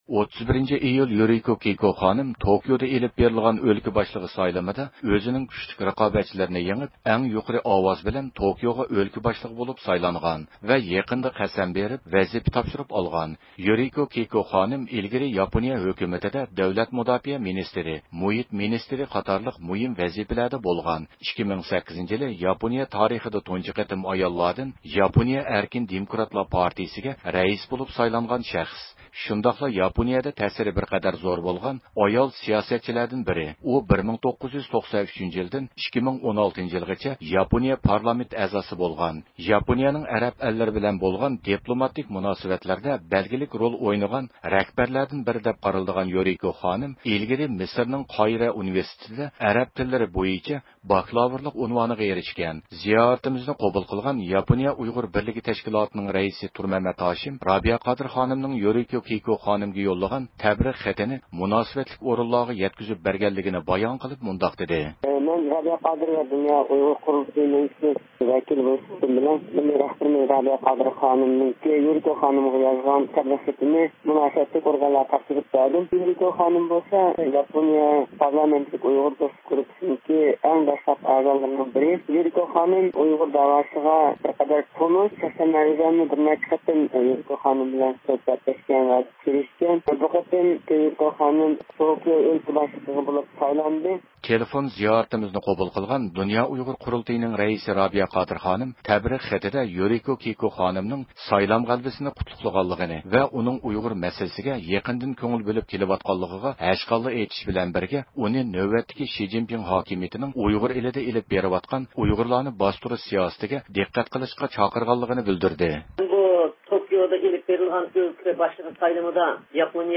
تېلېفون زىيارىتىمىزنى قوبۇل قىلغان دۇنيا ئۇيغۇر قۇرۇلتىيىنىڭ رەئىسى رابىيە قادىر خانىم تەبرىك خېتىدە يۇرىكو كېئىكو خانىمنىڭ سايلام غەلىبىسىنى قۇتلىغان ۋە ئۇنىڭ ئۇيغۇر مەسىلىسىگە يېقىندىن كۆڭۈل بۆلۈپ كېلىۋاتقانلىقىغا ھەشقاللا ئېيتىش بىلەن بىرگە، ئۇنى نۆۋەتتىكى شى جىنپىڭ ھاكىمىيىتىنىڭ ئۇيغۇر ئېلىدا ئېلىپ بېرىۋاتقان ئۇيغۇرلارنى باستۇرۇش سىياسىتىگە دىققەت قىلىشقا چاقىرغانلىقىنى بىلدۈردى.